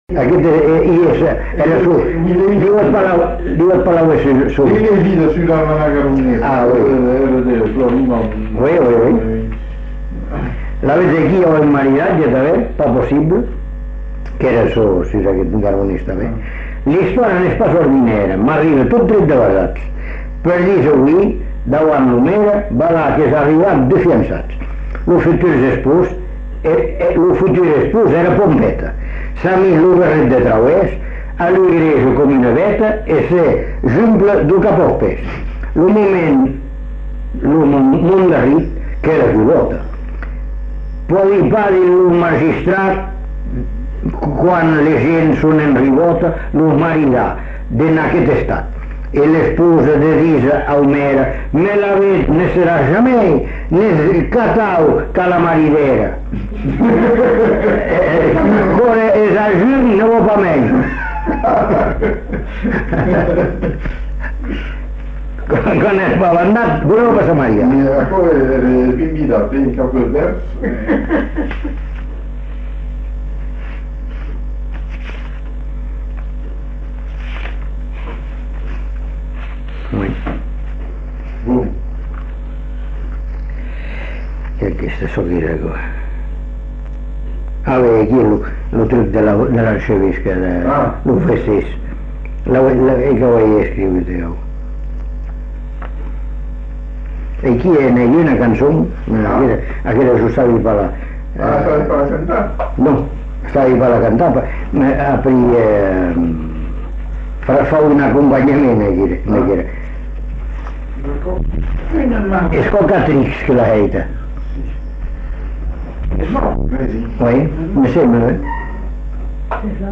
Aire culturelle : Bazadais
Lieu : Bazas
Genre : conte-légende-récit
Effectif : 1
Type de voix : voix d'homme
Production du son : lu